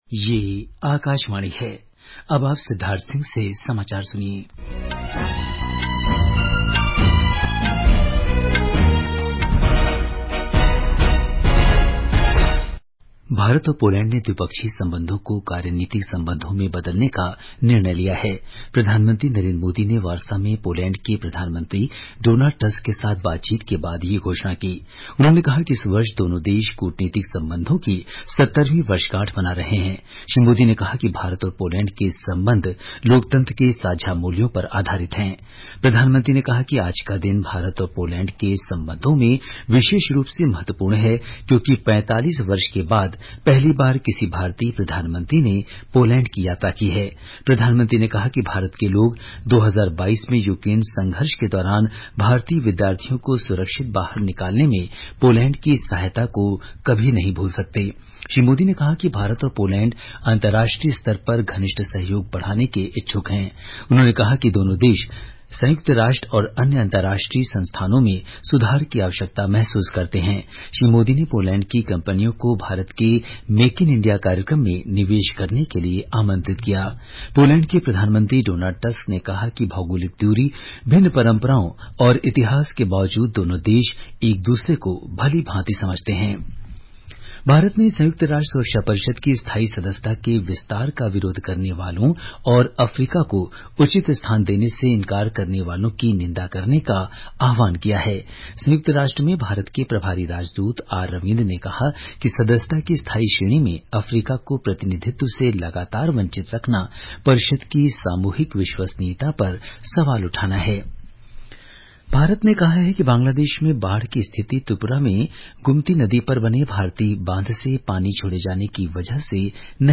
National Bulletins
प्रति घंटा समाचार